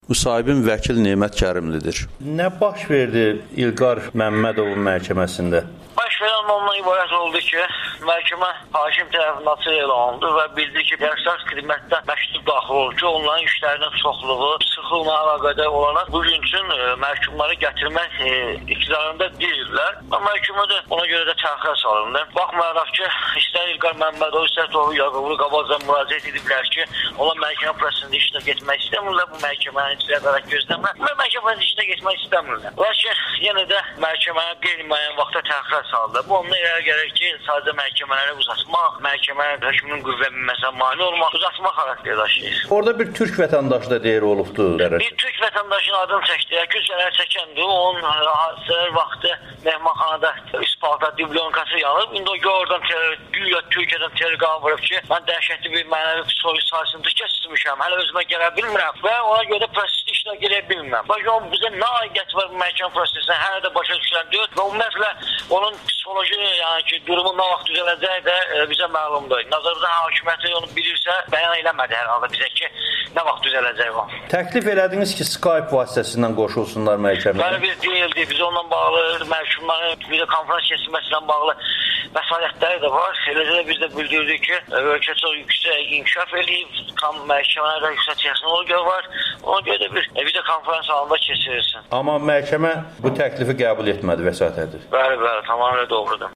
Apellyasiya Məhkəməsində İ.Məmmədovun və T.Yaqublunun işinə təkrar baxılması təxirə salınıb [Audio-Müsahibə]
Amerikanın Səsinə müsahibəsi